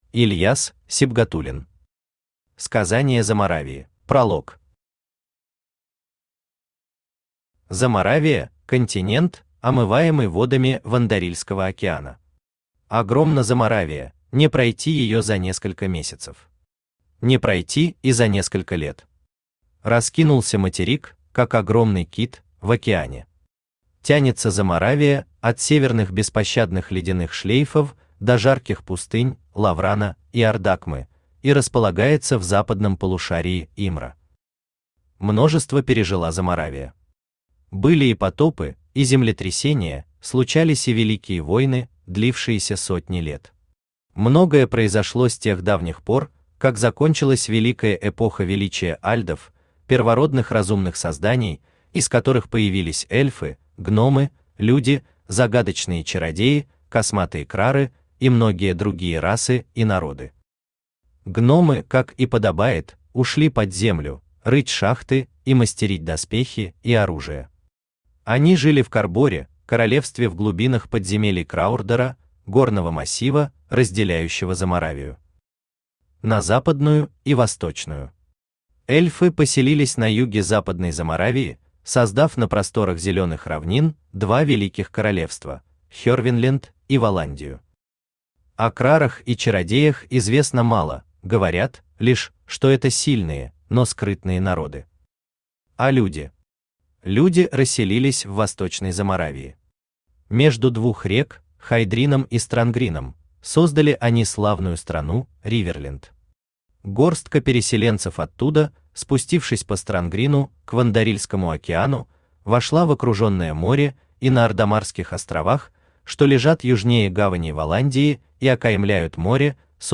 Аудиокнига Сказания Заморавии | Библиотека аудиокниг
Aудиокнига Сказания Заморавии Автор Ильяс Сибгатулин Читает аудиокнигу Авточтец ЛитРес.